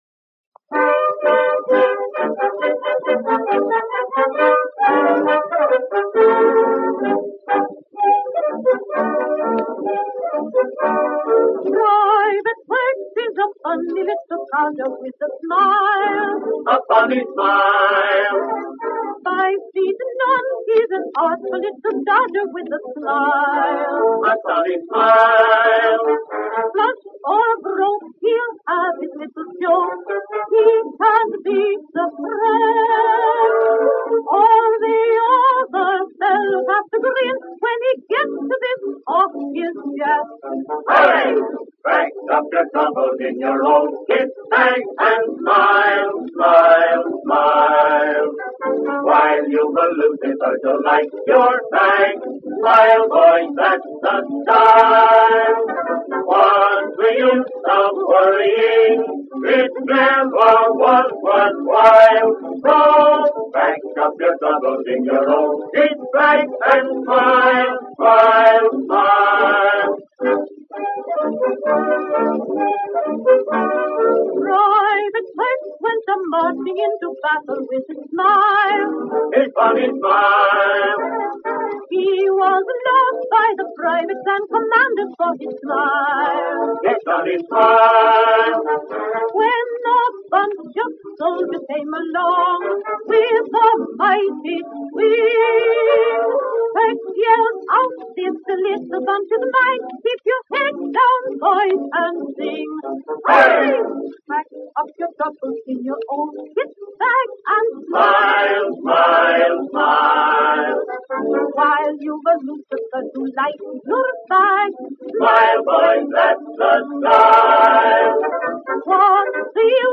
This is the version sung by Helen Clark.